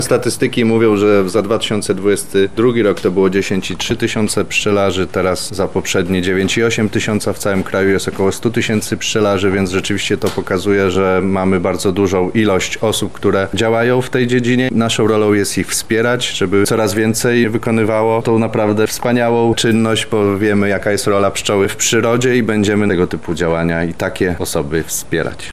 – mówi Marek Wojciechowski, wicemarszałek Województwa Lubelskiego.